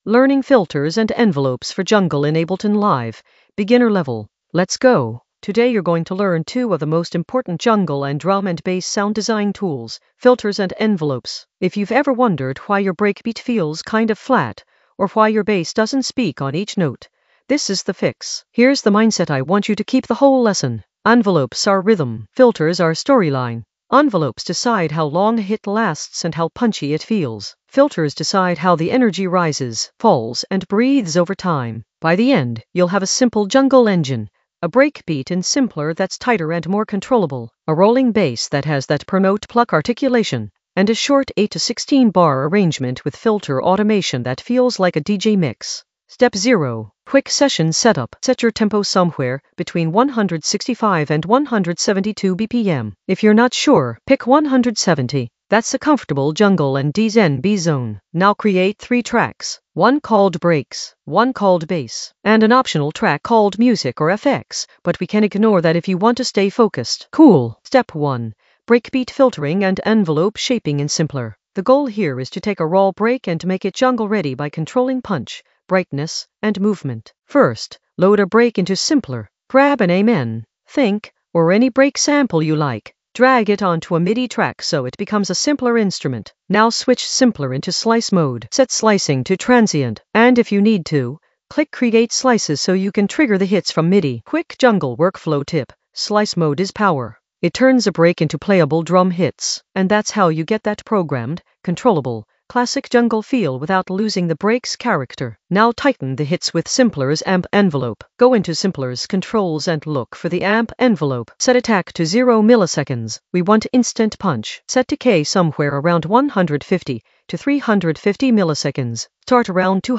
An AI-generated beginner Ableton lesson focused on Learning filters and envelopes for jungle in the Sound Design area of drum and bass production.
Narrated lesson audio
The voice track includes the tutorial plus extra teacher commentary.